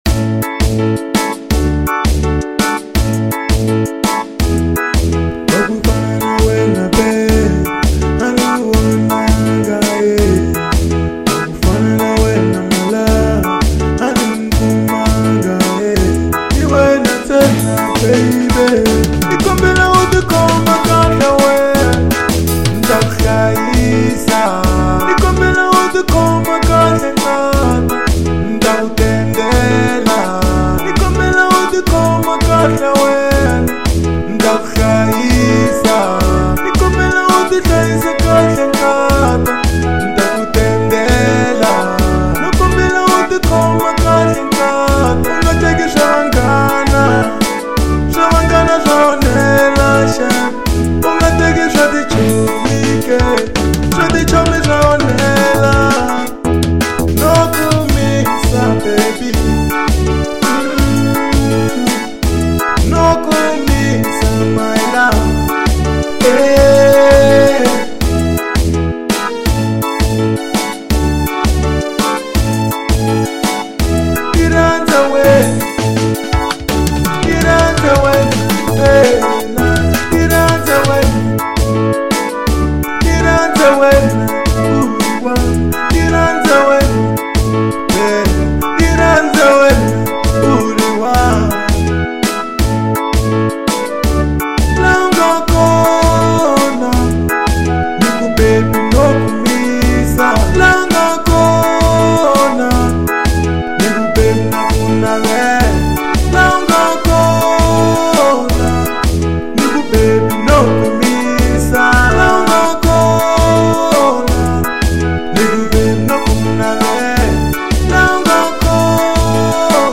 04:21 Genre : RnB Size